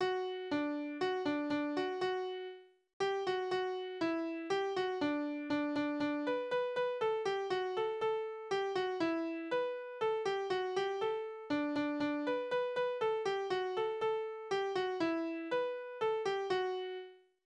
Tonart: D-Dur
Taktart: 3/4
Tonumfang: große Sexte
Besetzung: vokal